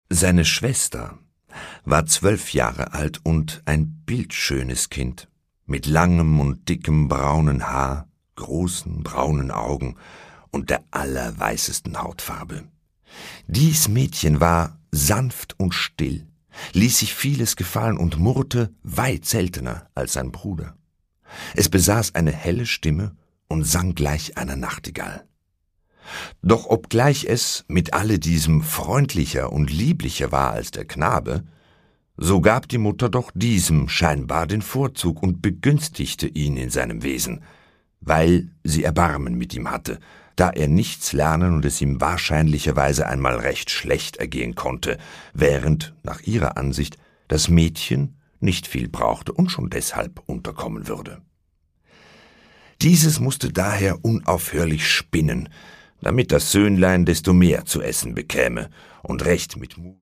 Gottfried Keller: Pankraz, der Schmoller (Ungekürzte Lesung)
Produkttyp: Hörbuch-Download
Gelesen von: Dieter Moor